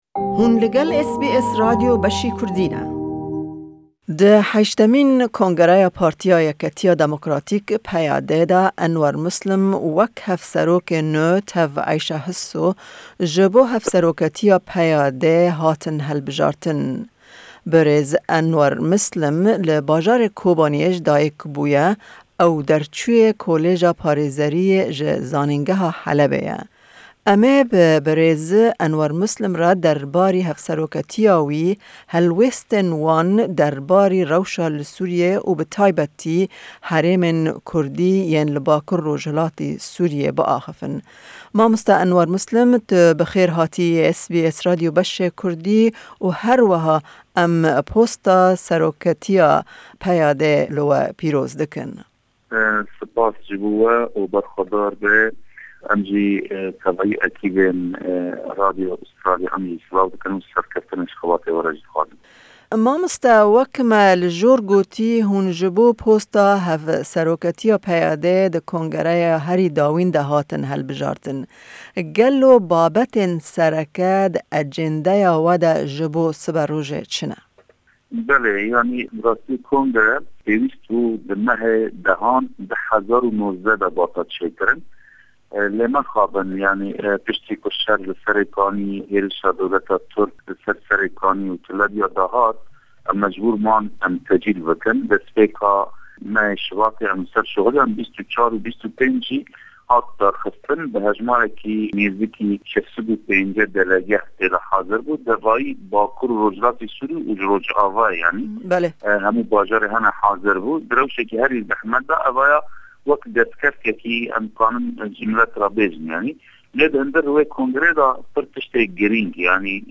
Em hevpeyvîneke taybet bi berêz Enwer Mislim re derbarî hevseroketiya wî pêk tînin.